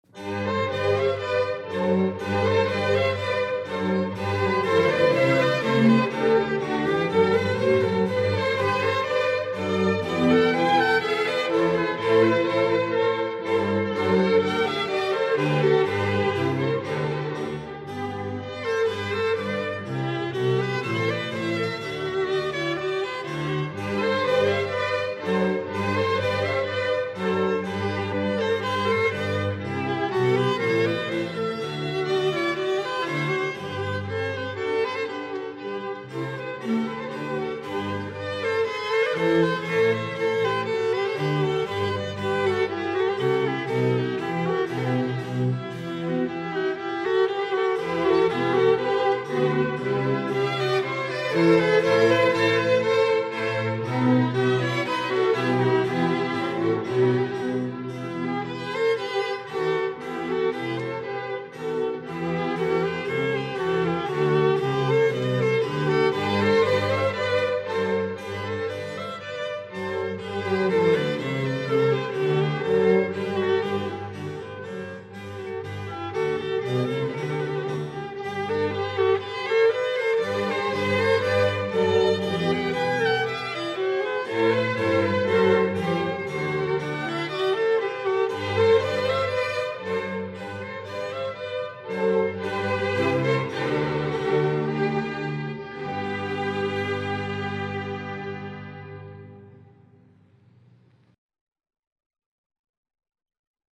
Avec douceur.